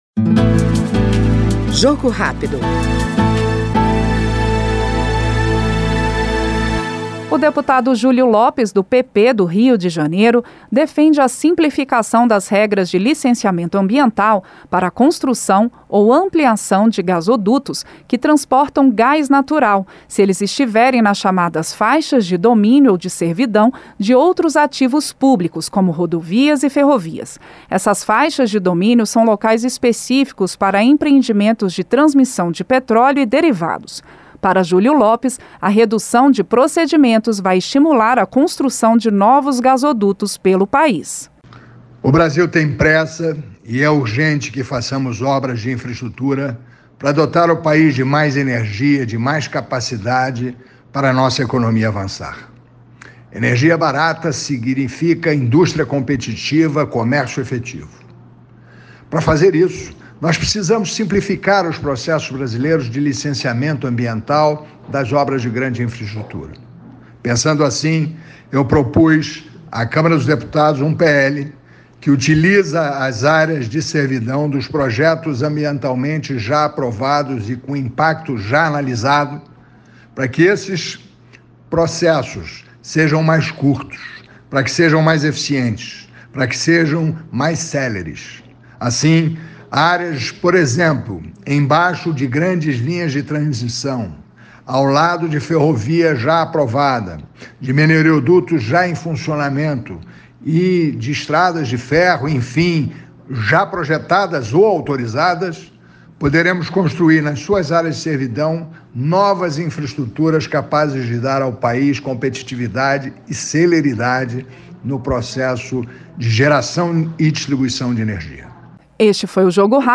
Jogo Rápido é o programa de entrevistas em que o parlamentar expõe seus projetos, sua atuação parlamentar e sua opinião sobre os temas em discussão na Câmara dos Deputados.